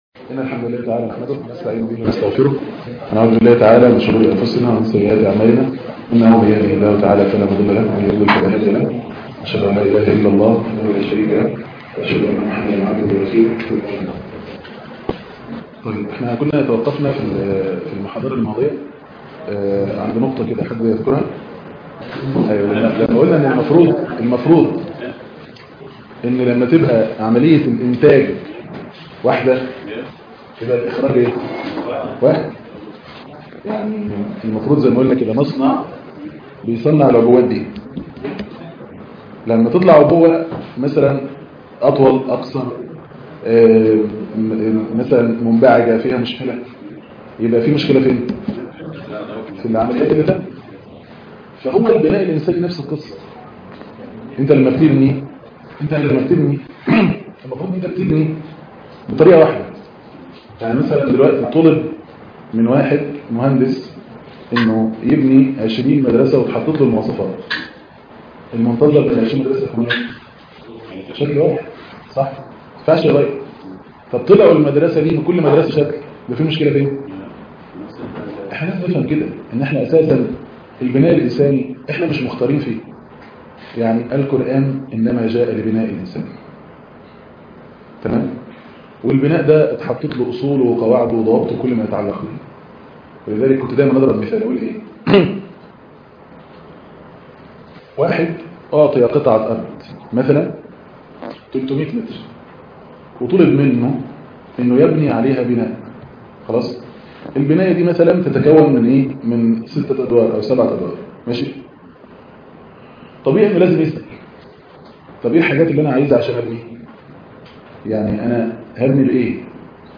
المحاضرة الثالثة من الاسبوع الاول في أصول التعامل مع الأطفال - صناعة الطفل القرآني